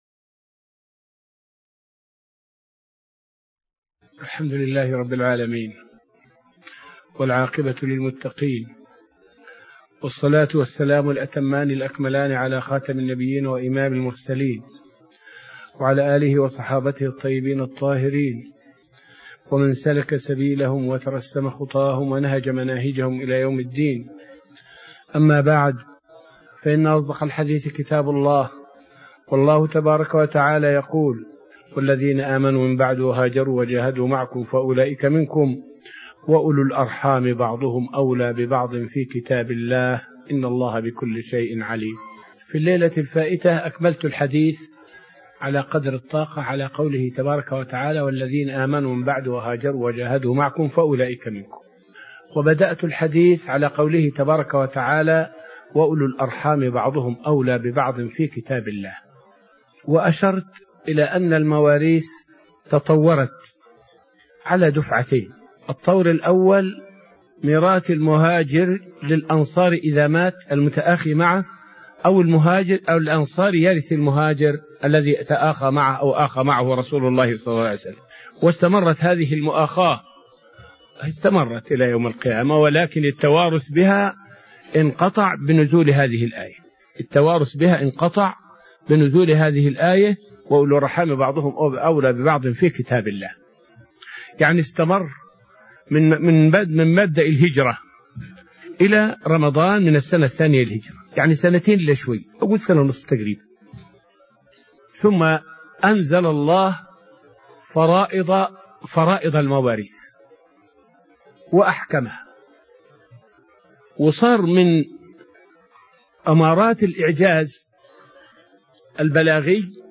الدرس التاسع من دروس تفسير سورة الأنفال والتي ألقاها الشيخ في رحاب المسجد النبوي حول الآيات من الآية 45 إلى الآية 49.